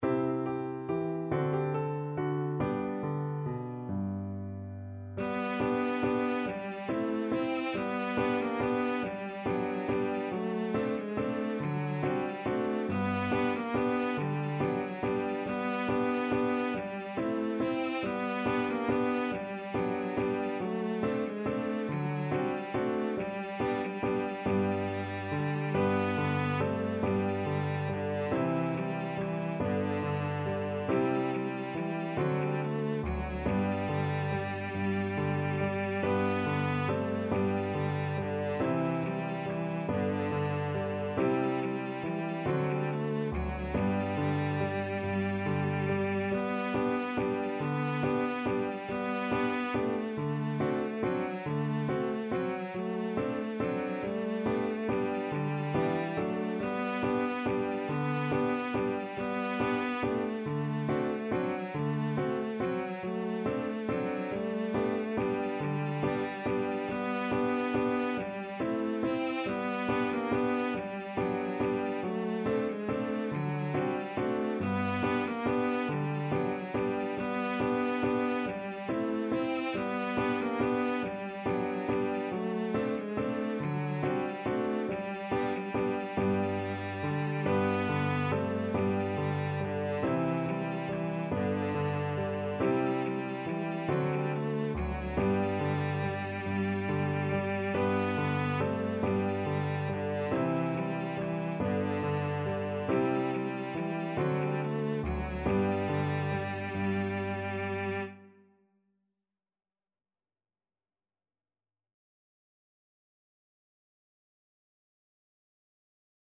Cello version
Score Key: G major (Sounding Pitch)
Time Signature: 6/8
Tempo Marking: Steadily = 140
Instrument: Cello
Style: Traditional